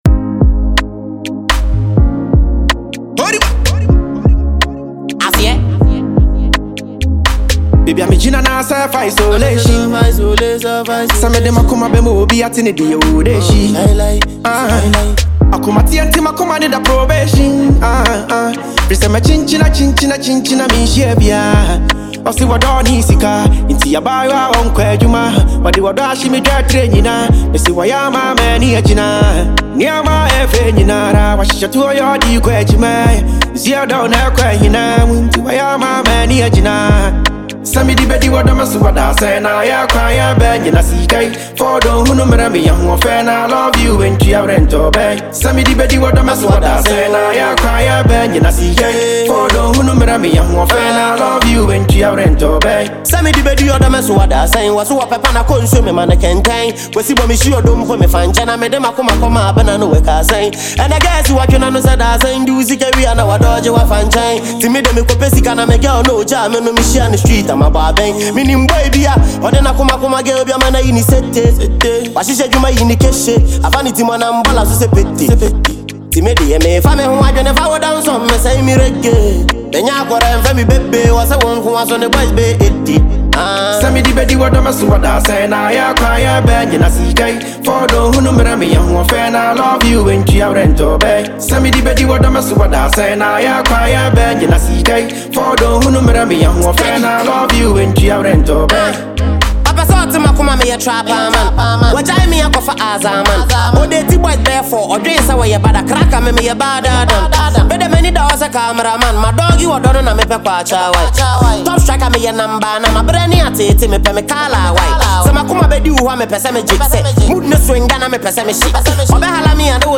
rap sensation
With gritty verses and emotional resonance
backed by a hard-hitting beat and intense lyrical depth.